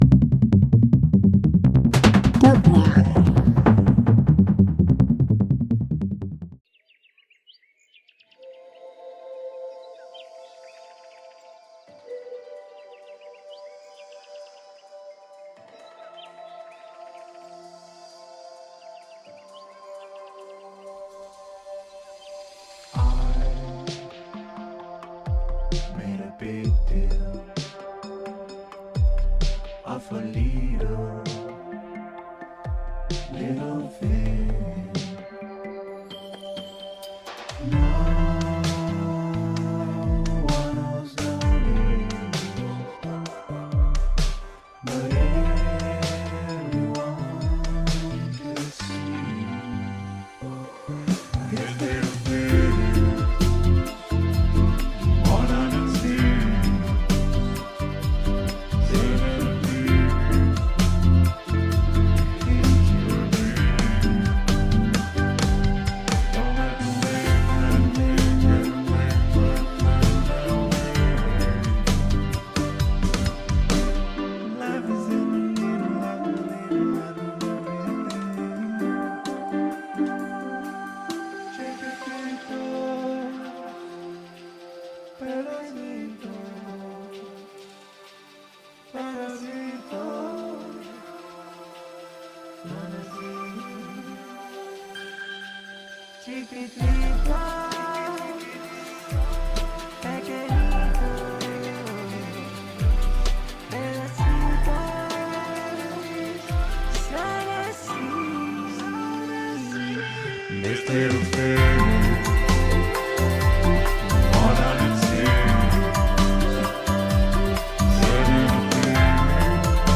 Interview Talk Show